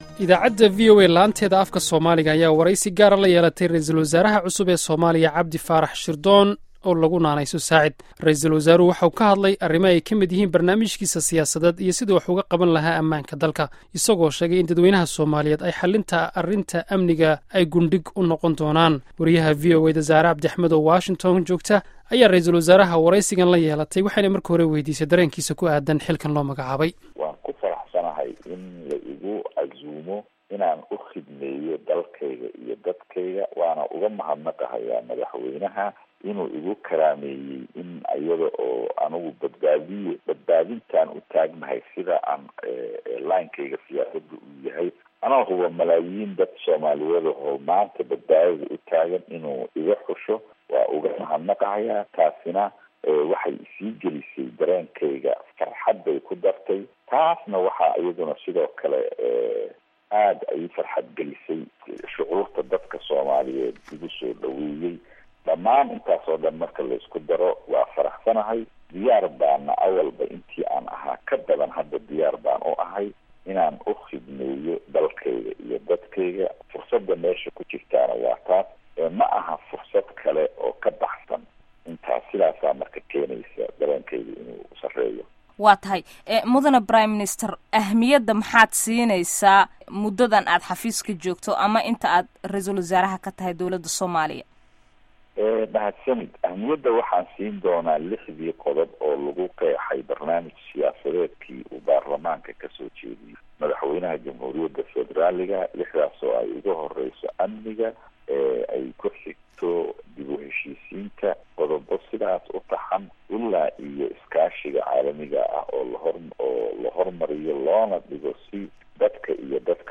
VOA oo Wareysi Gaar ah la Yeelatay RW Saacid
Wareysiga RW Saacid